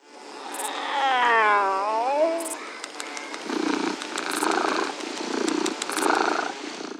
Les sons vont au-delà des simples cris des animaux. Ils restituent l’univers de ce moment de la journée, à la campagne.
10_chat_Mix.wav